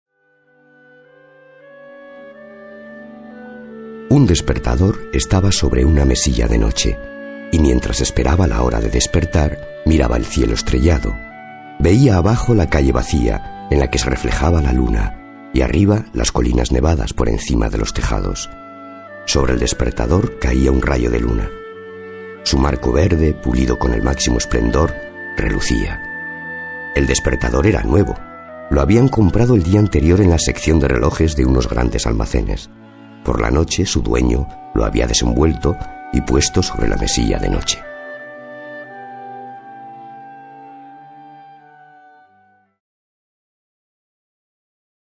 voz grave, adulto, constitucional
Sprechprobe: Sonstiges (Muttersprache):